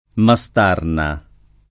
[ ma S t # rna ]